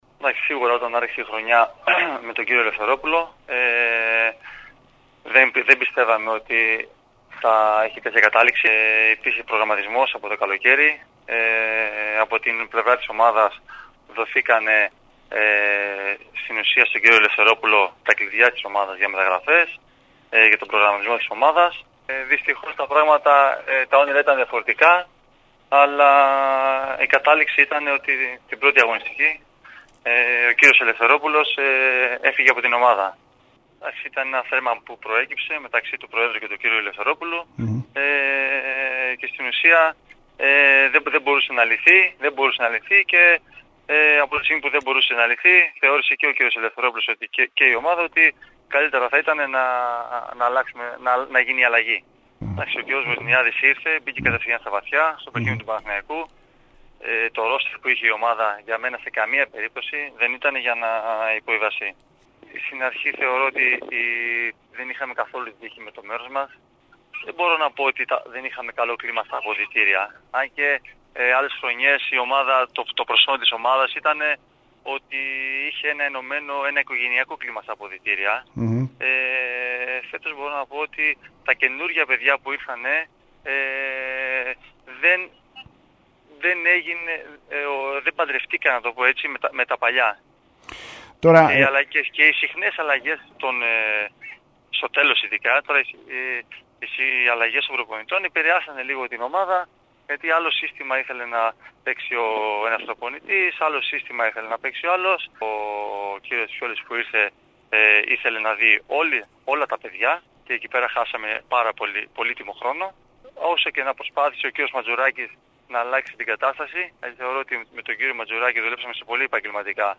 Ενημέρωση